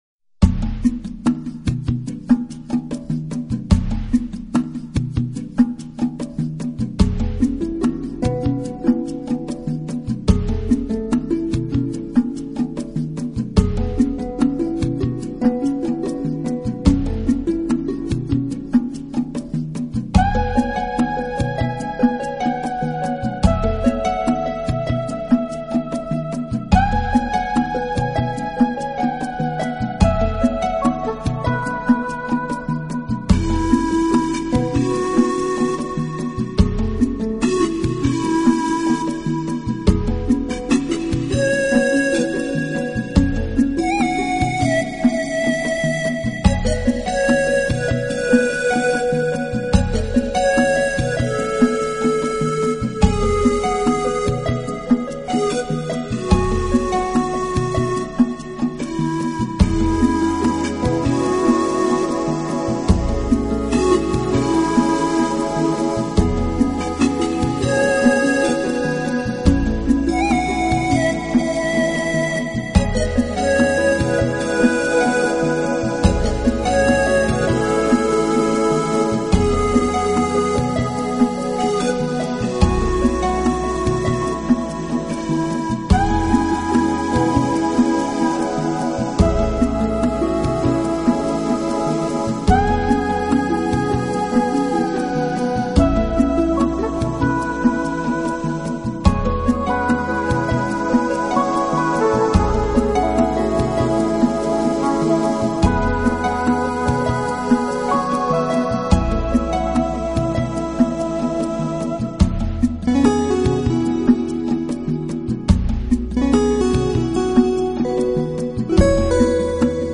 音乐流派：  New  Age